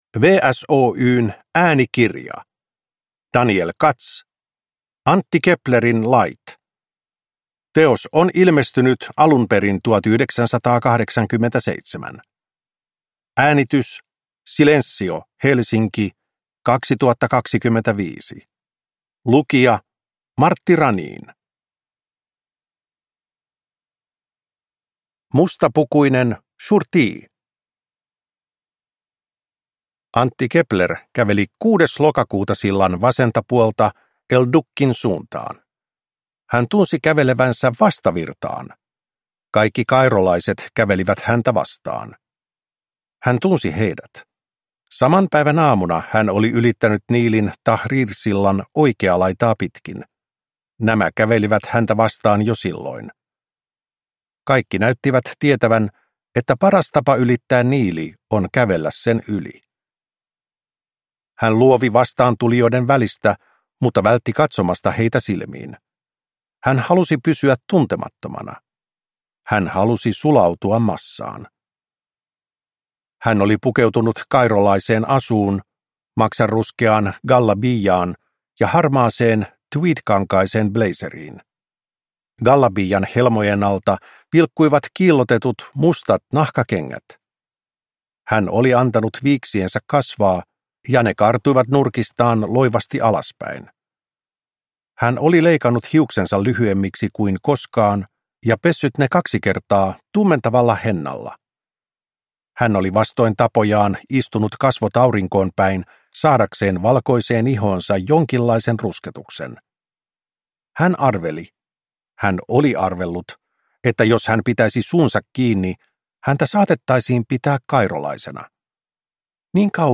Antti Keplerin lait – Ljudbok